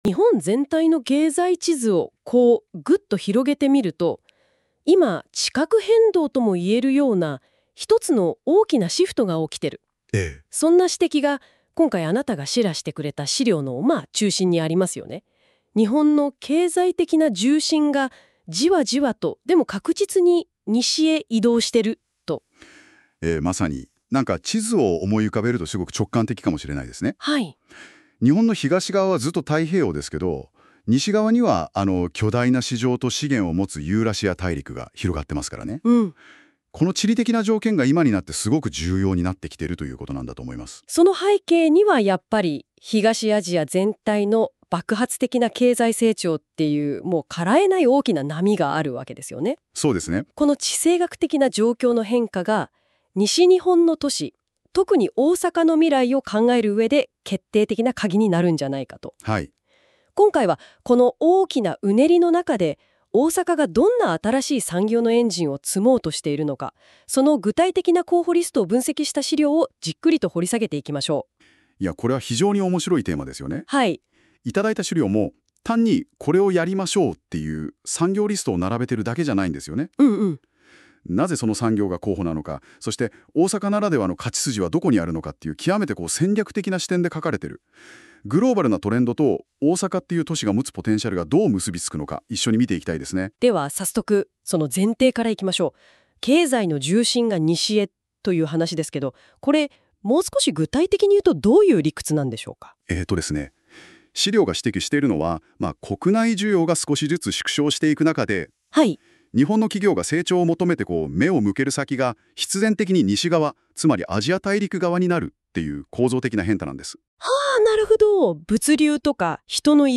音声解説（NotebookLM作成）